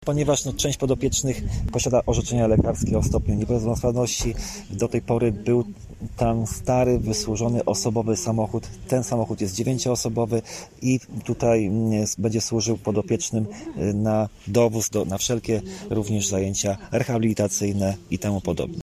Mówi starosta sandomierski Marcin Piwnik: